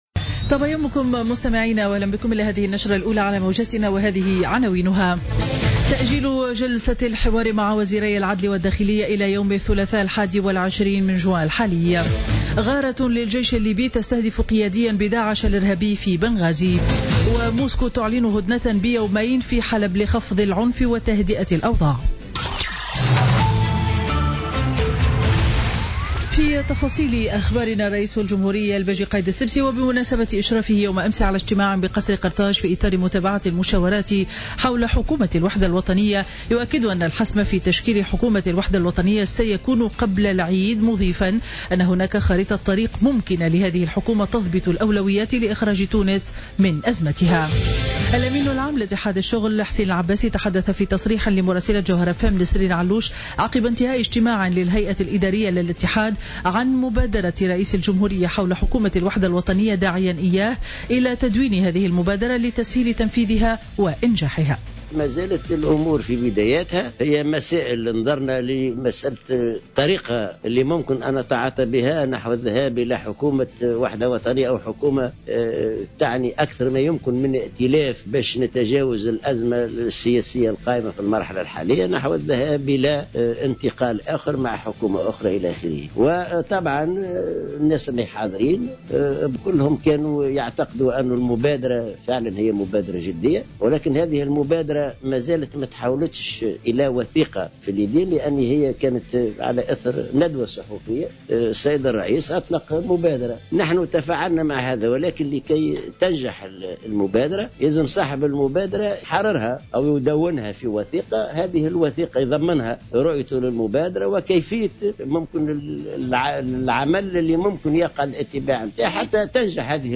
نشرة أخبار السابعة صباحا ليوم الخميس 16 جوان 2016